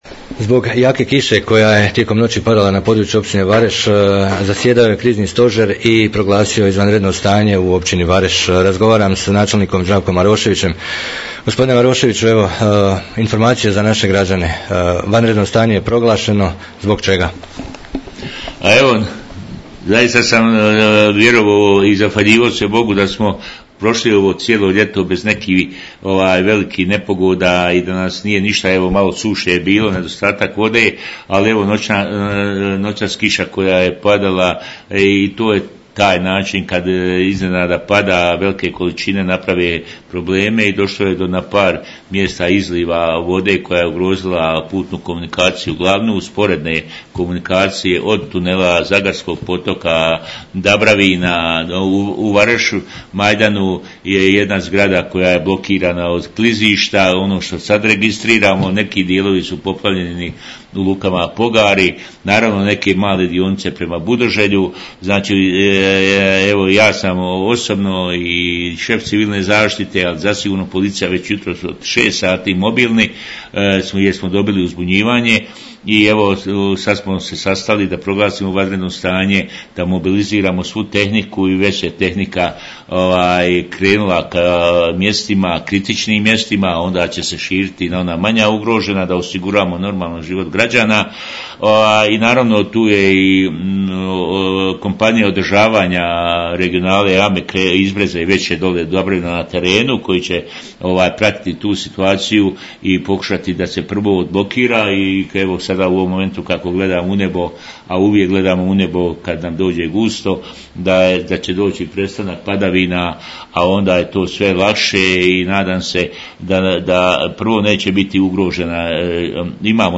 Poslušajte i izjavu načelnika Zdravka Maroševića.